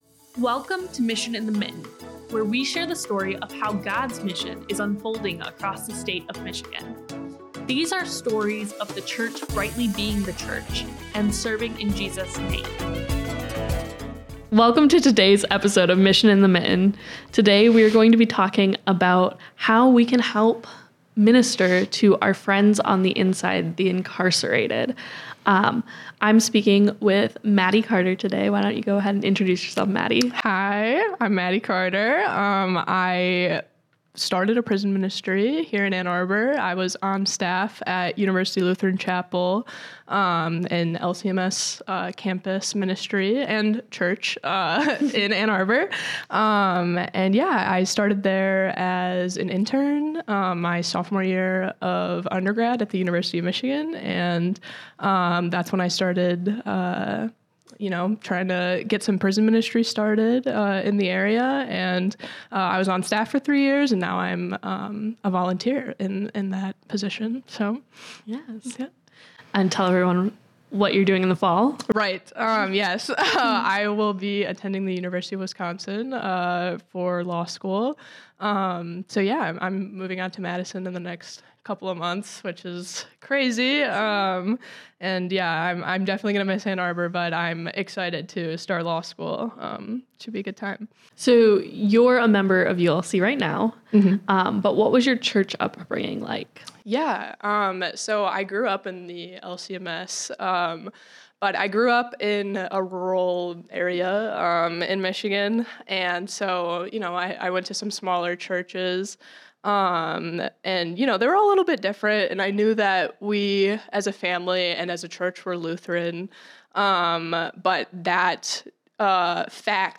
The conversation also touches on future aspirations for the ministry and resources for those interested in getting involved.